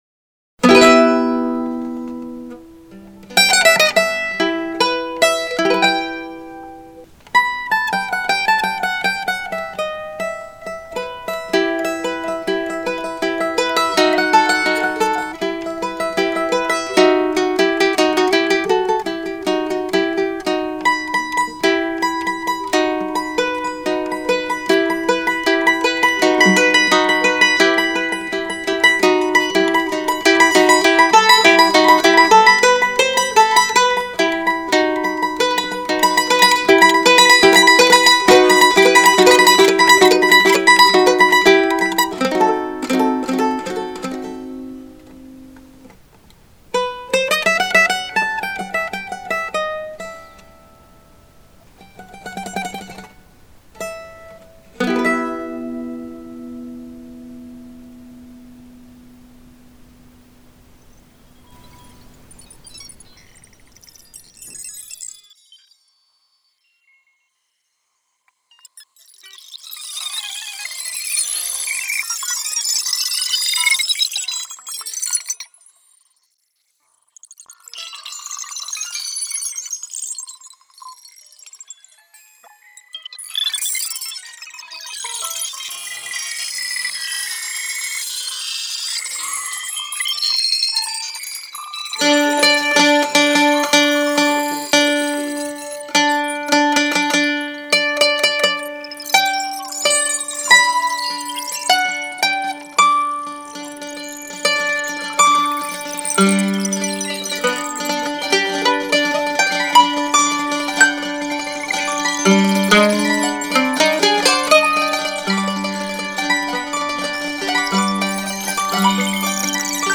mandolino
The sculpture features a mandolin which has been "augmented" by inserting 3D-printed trumpets that act as resonators. For the instrument, the artist has composed a score which has been performed by a musician and subsequently edited thanks to a series of digital audio processes which have subsequently been organized compositionally.
In this way the resulting sound absorbs all the resonances of the instrument and of the "prostheses" attached to them, giving it an incredible truthfulness.